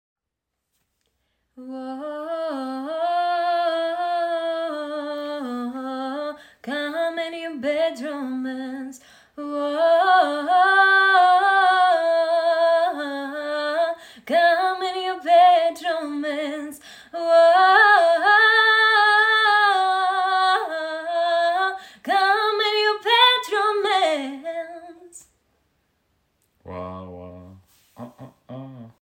Гарний голос🔥🔥🔥
Прекрасний голос😍